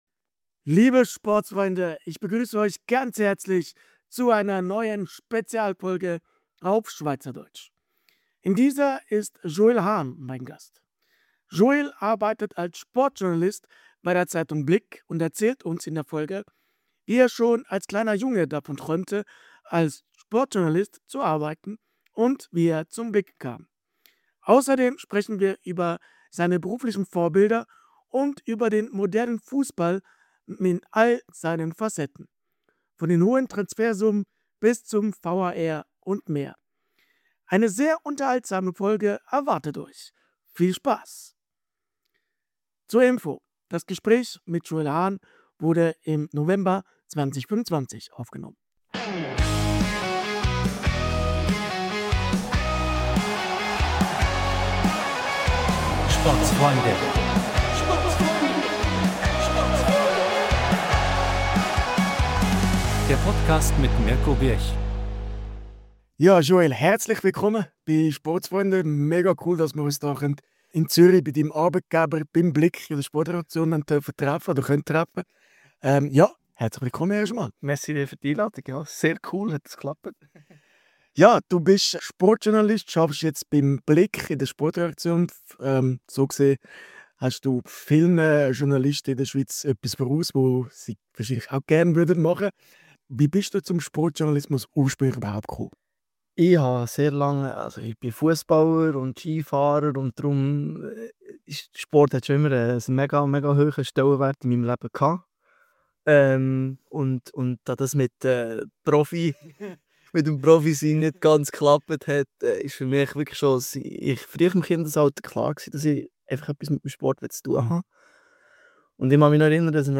Wir starten mit einer neuen SPEZIALFOLGE auf SCHWEIZERDEUTSCH in die Woche!